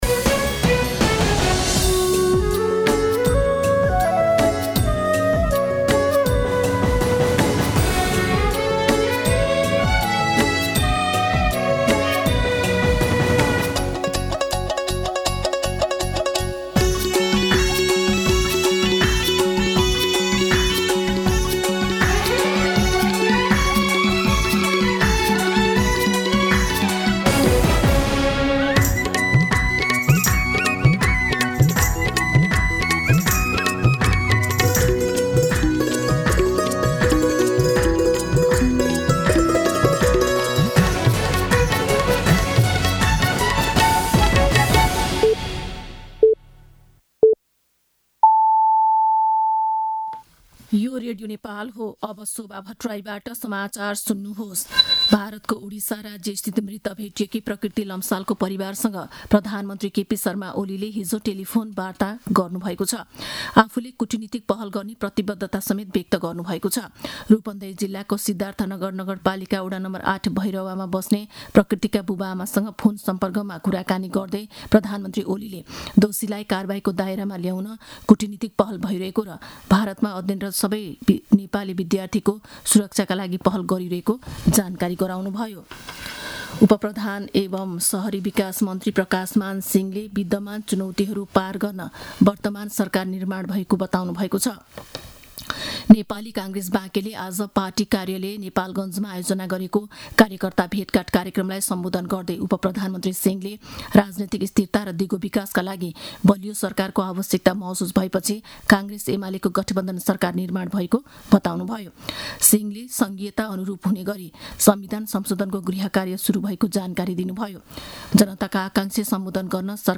मध्यान्ह १२ बजेको नेपाली समाचार : १४ फागुन , २०८१